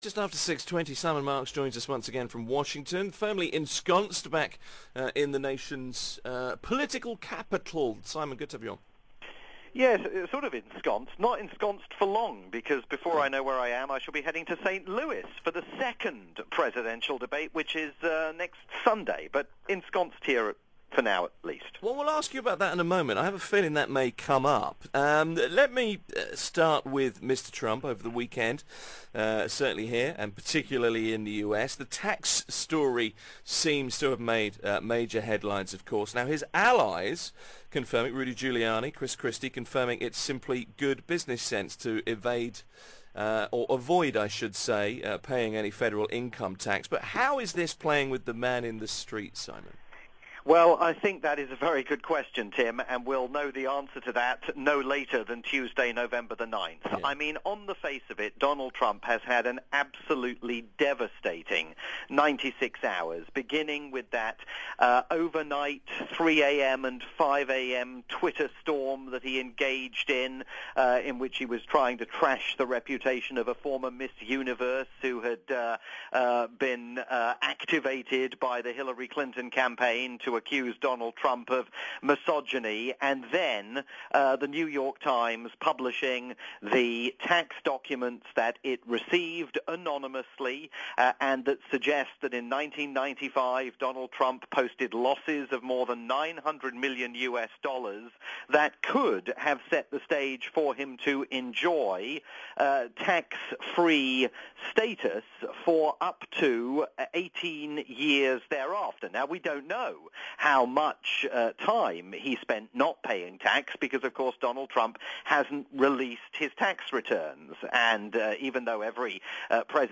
October 3, 2016 - Weekly News Roundup